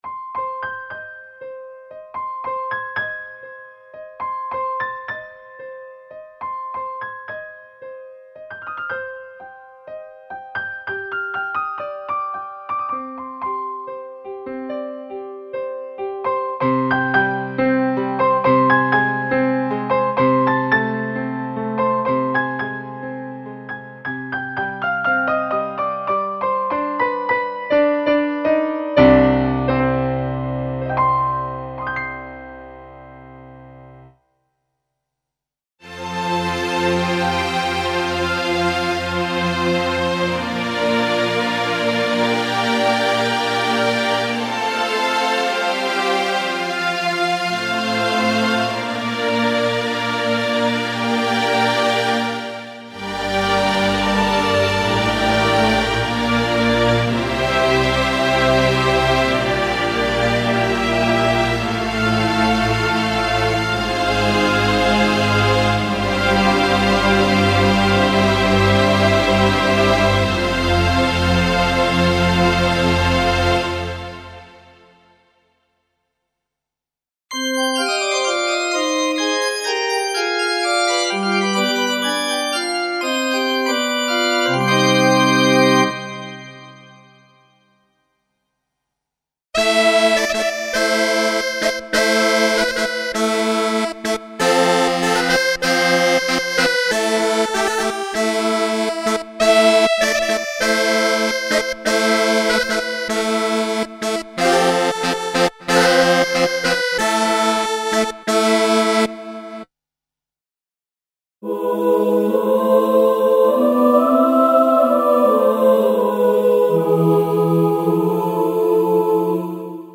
Comprehensive collection of classical instruments (acoustic pianos, guitars, pipe organs, strings, flutes, trumpets, saxophones, brass sections) and voices.